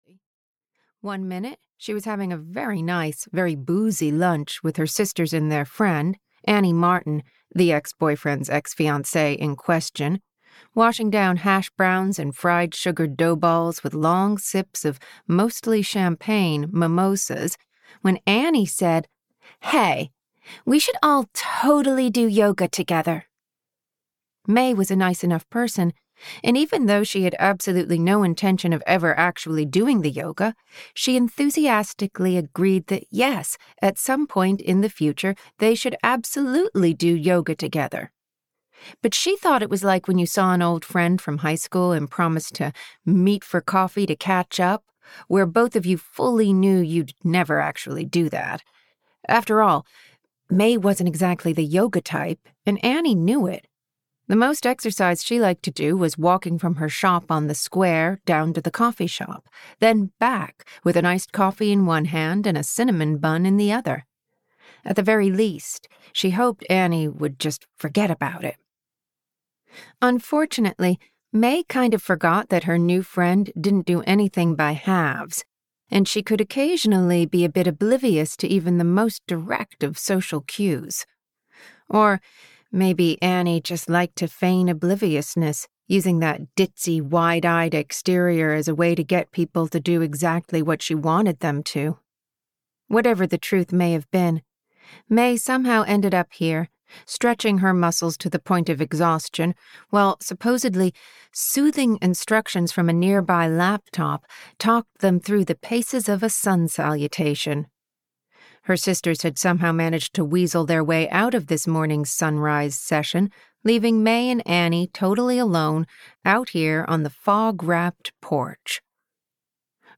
Sweet Pea Summer (EN) audiokniha
Ukázka z knihy